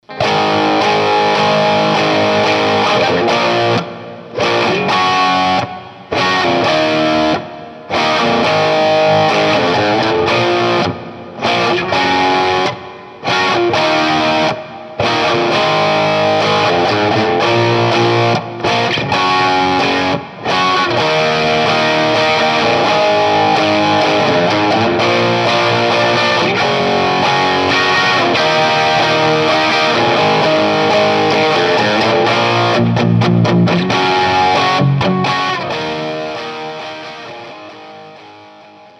TONE SAMPLES - All clips plugged straight into amp unless noted.
1/2 watt, Old Dimarzio Paf - T-Top like pickup.
All samples done on the original prototype using the original output